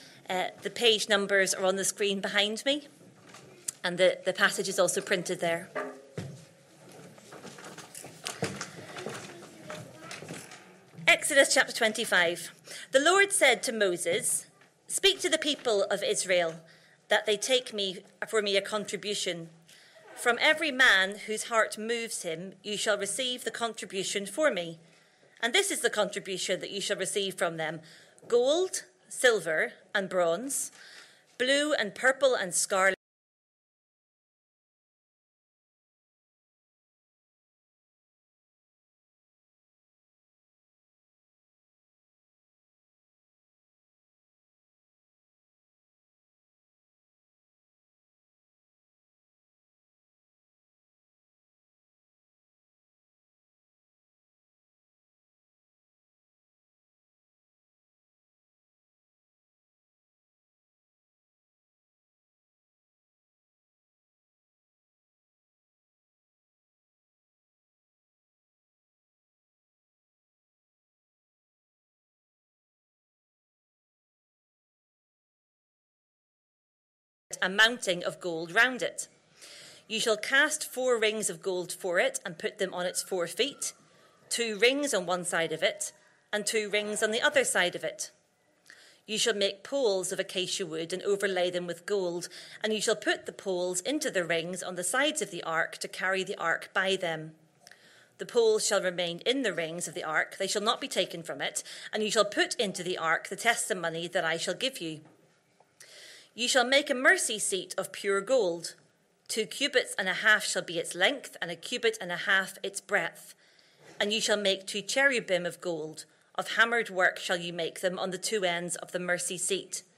Christ Church Sermon Archive